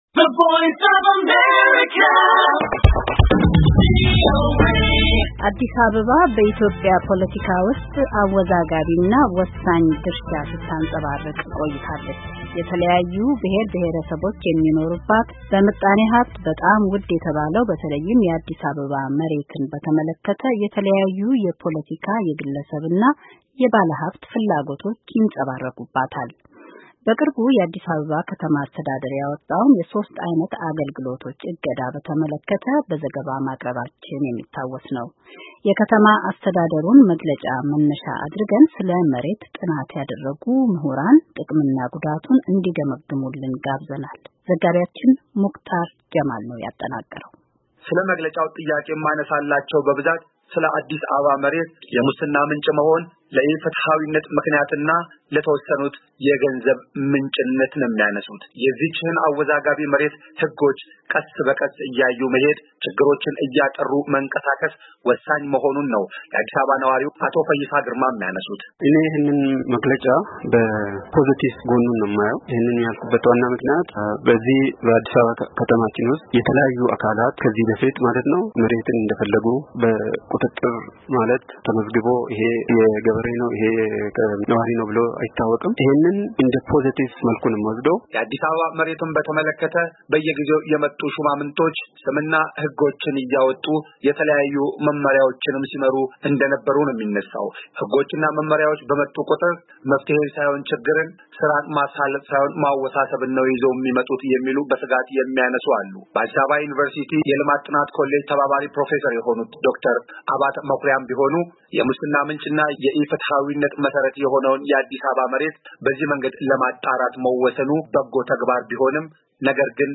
የባለሞያዎችን ሐሳብ አካቶ ተከታዩን ይዟል።